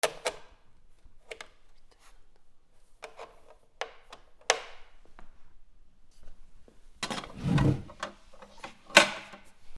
『チャチャ』